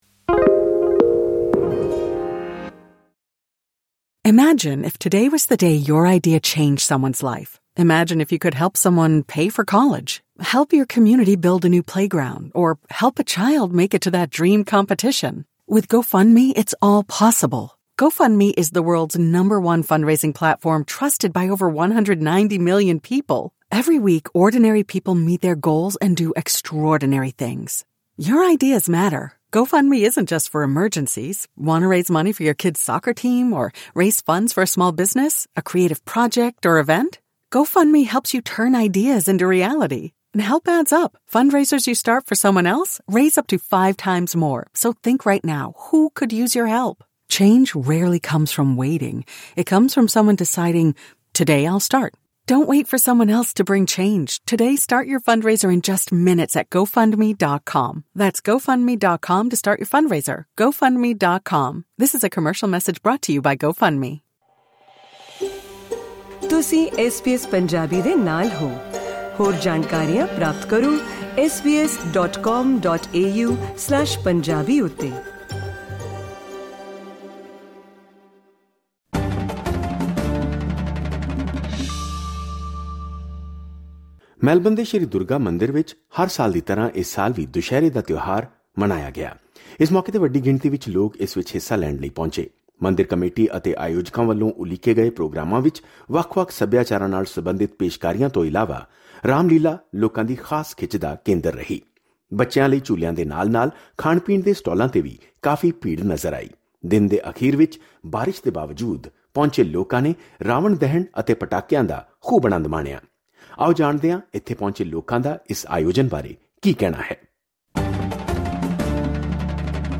The festival of Dussehra was celebrated with full enthusiasm and devotion at the Shri Durga Temple in Melbourne. On this occasion, people who arrived enjoyed the Ramlila and other performances, while there was also a lot of excitement at the food stalls and rides. Let's know what the people who arrived here have to say about this event through this podcast.